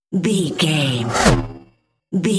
Pass by sci fi fast
Sound Effects
futuristic
pass by
vehicle